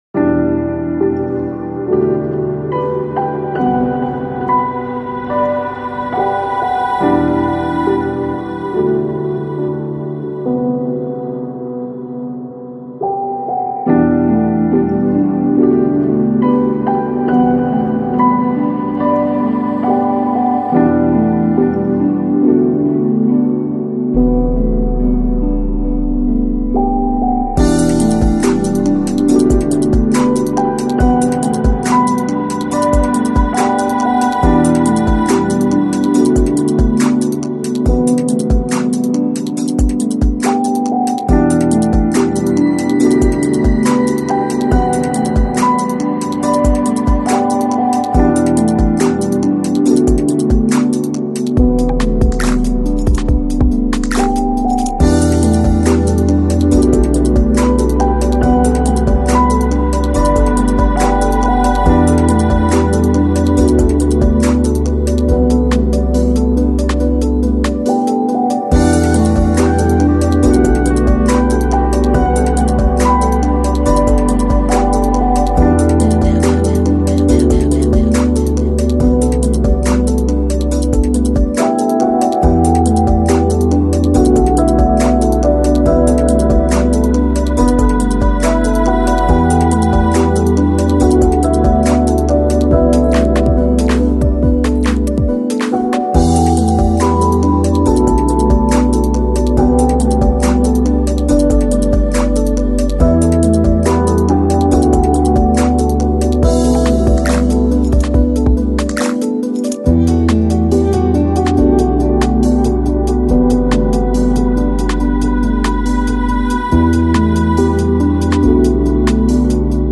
Жанр: Chill Out, Trip-Hop, Lounge